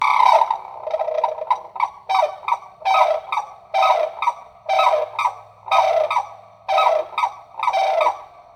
Unison Call | A duet performed by a pair, to strengthen their bond and protect their territory.
White-naped-Crane-Unison.mp3